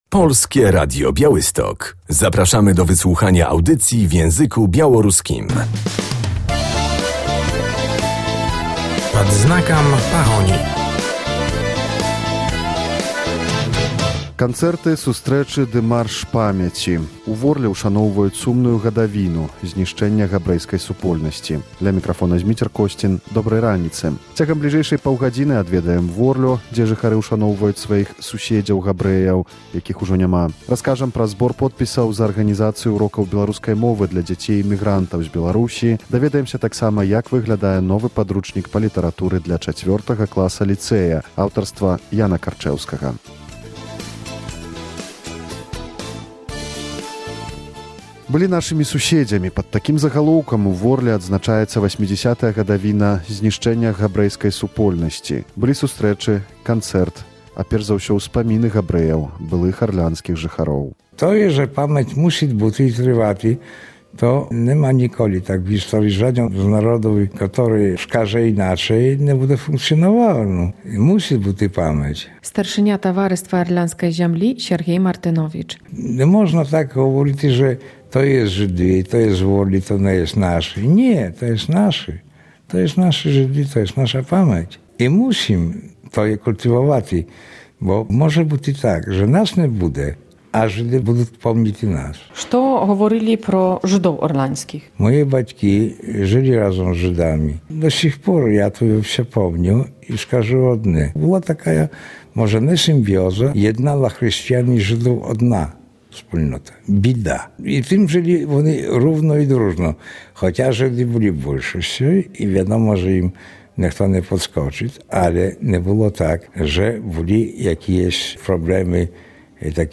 W audycji będziemy na upamiętnieniu 80. rocznicy zagłady społeczności żydowskiej w Orli.